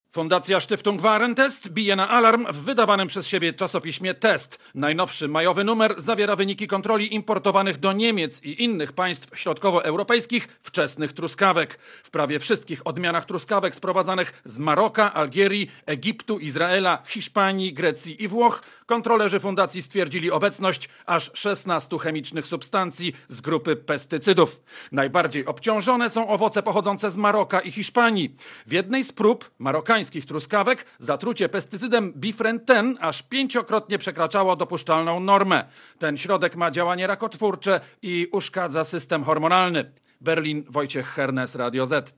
Korespondencja z Berlina (330Kb)